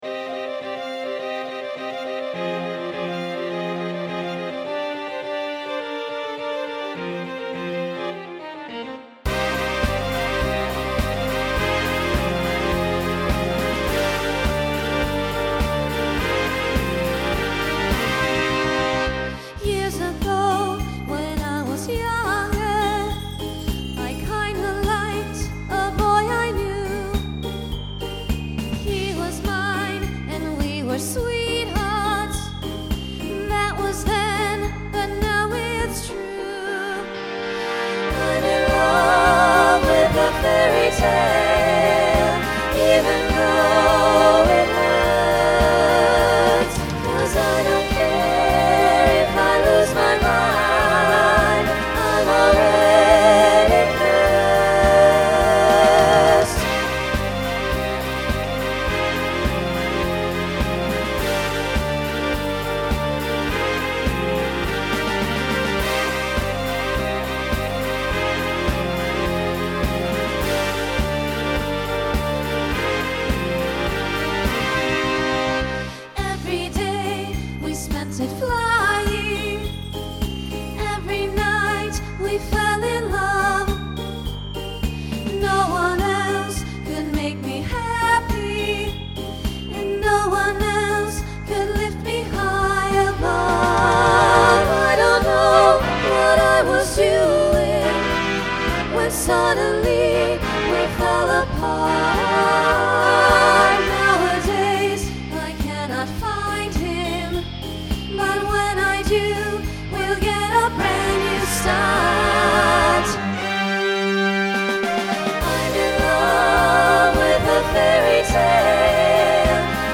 Voicing SSA Instrumental combo Genre Pop/Dance
Mid-tempo